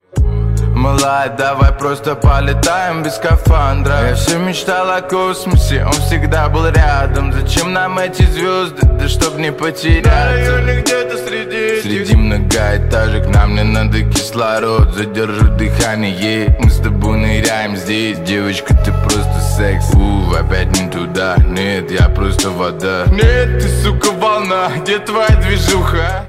• Качество: 128, Stereo
грустные
русский рэп
спокойные
качающие